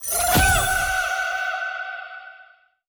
wild_1_win.wav